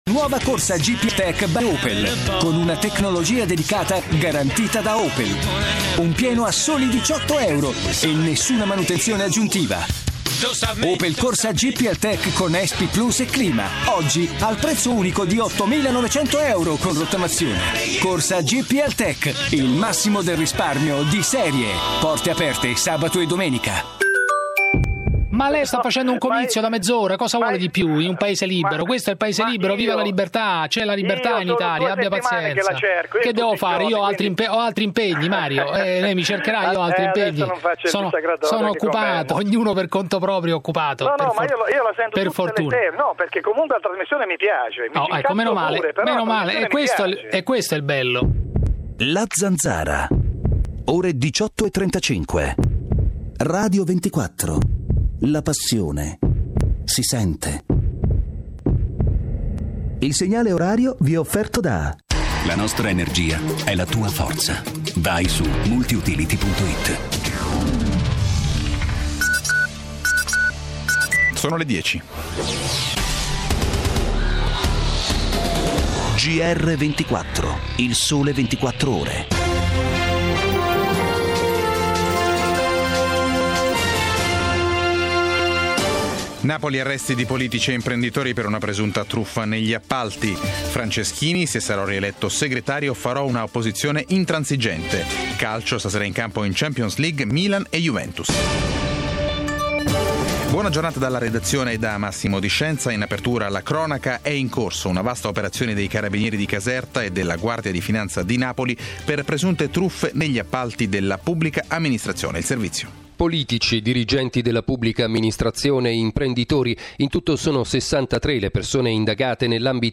Radio 24, del gruppo "Il Sole 24 ore", ha effettuato un collegamento in una apposita rubrica dedicata alle migliori iniziative in Italia legate all'evento.
Ascolta la registrazione della trasmissione del 16 ottobre 2009 (il collegamento con Pozzallo è al minuto 5.25 della registrazione)